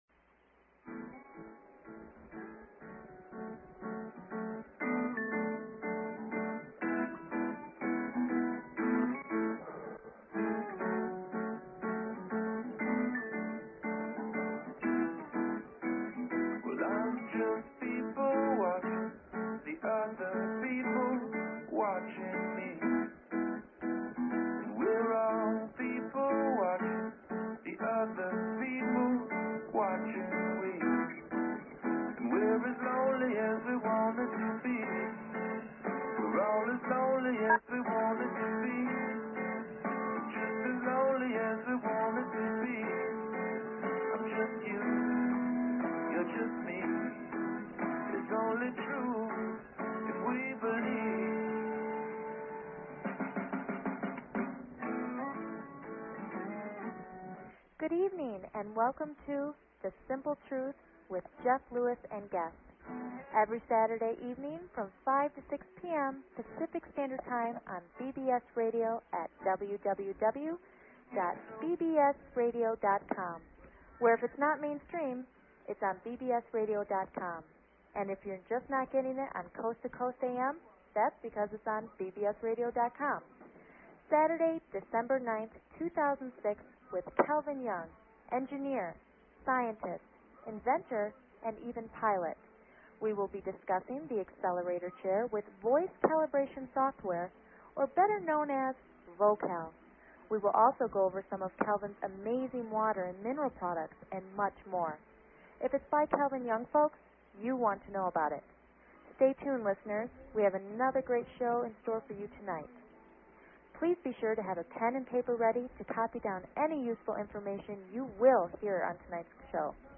Talk Show Episode, Audio Podcast, The_Simple_Truth and Courtesy of BBS Radio on , show guests , about , categorized as